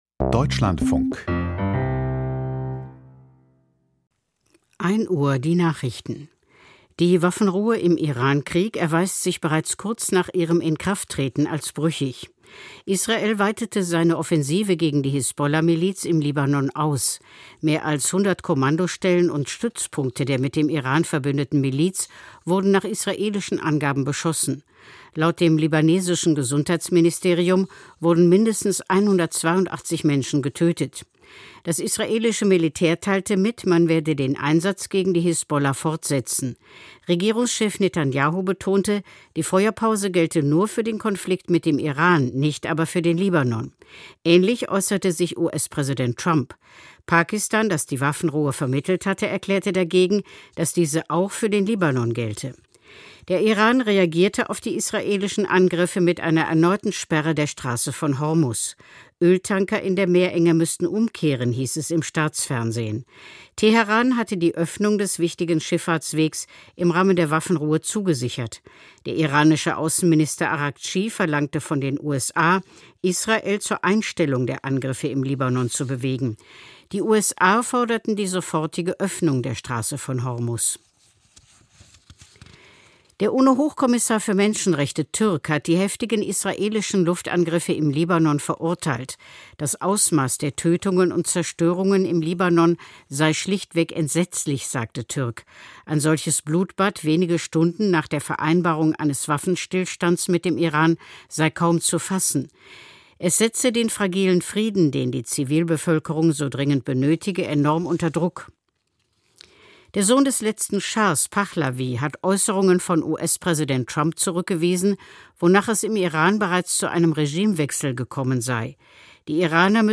Die Nachrichten vom 09.04.2026, 01:00 Uhr